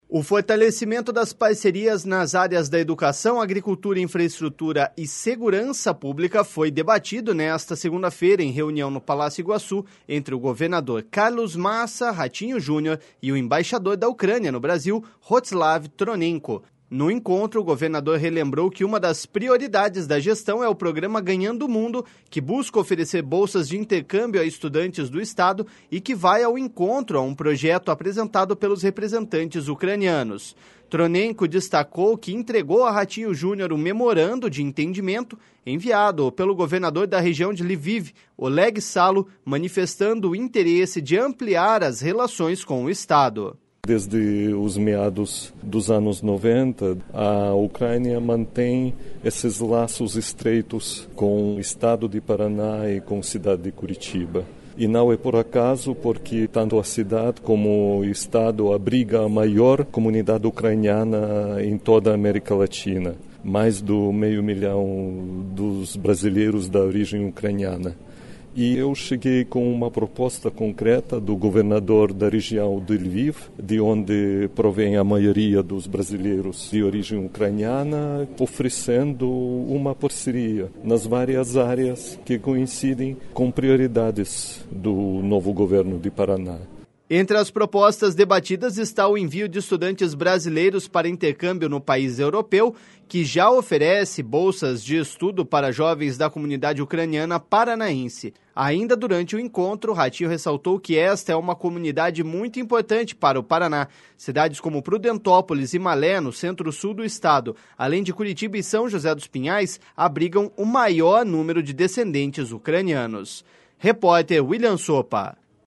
Tronenko destacou que entregou a Ratinho Junior um memorando de entendimento enviado pelo governador da região de Lviv, Oleg Salo, manifestando o interesse em ampliar as relações com o Estado.// SONORA ROSTYSLAV TRONENKO.//